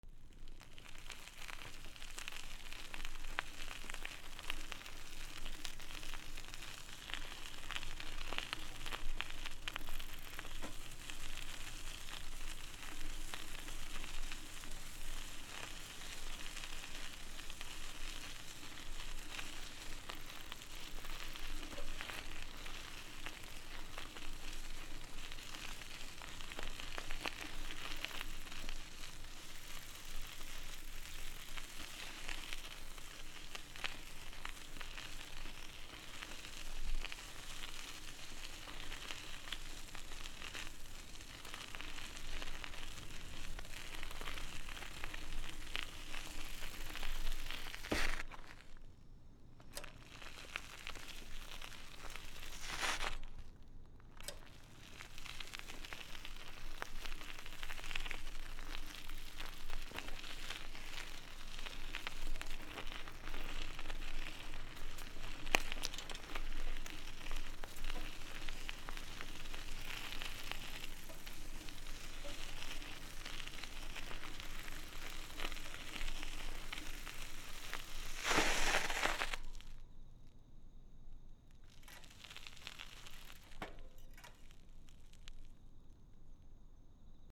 自転車 こぎながら録音 住宅街 DL
/ E｜乗り物 / E-45 ｜自転車